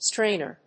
アクセント・音節stráin・er
音節strain･er発音記号・読み方stréɪnər